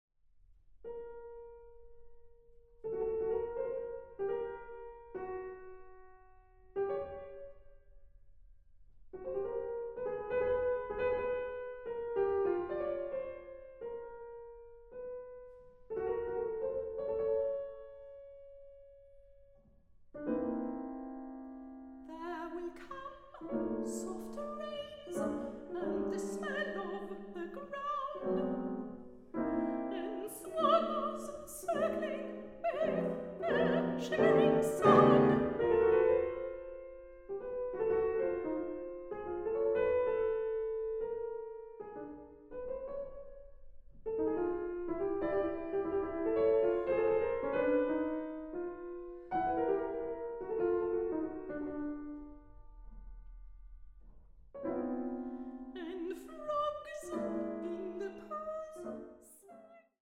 Soprano
Clarinet
Piano
Recording: Tonstudio Ölbergkirche, Berlin, 2023